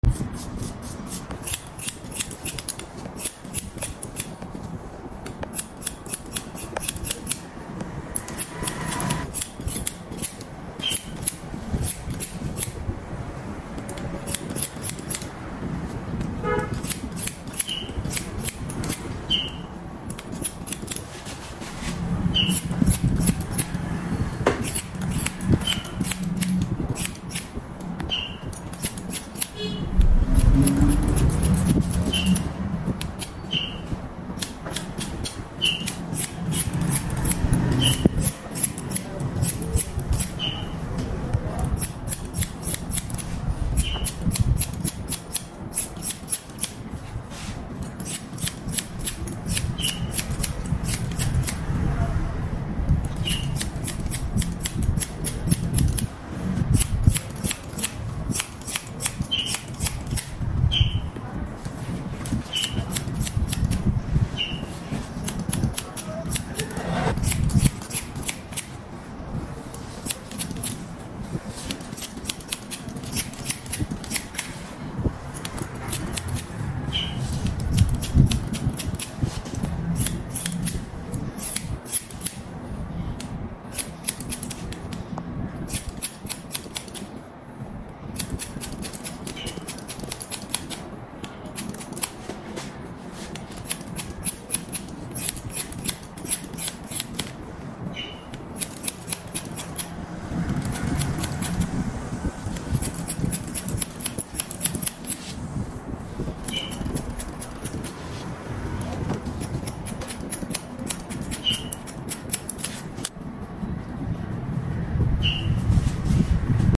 Satisfying White Haircut Sounds | Sound Effects Free Download